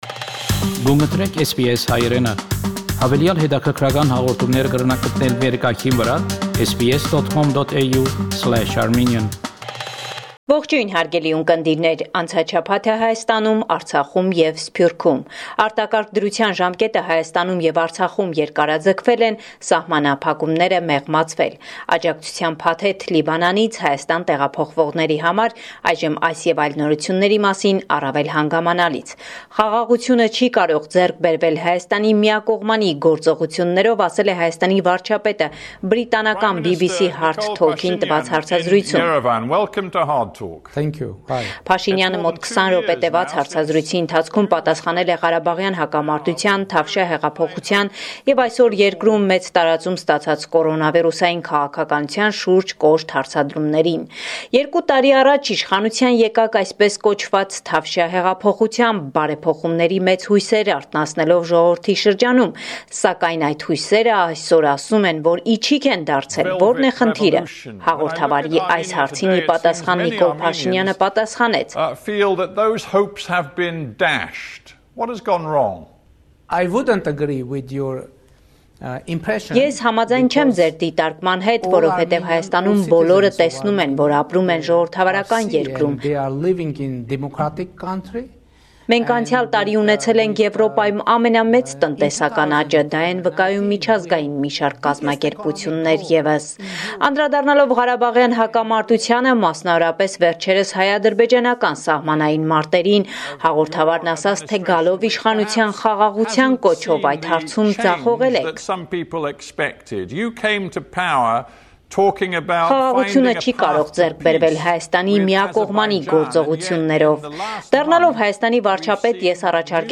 Latest News from Armenia – 18 August 2020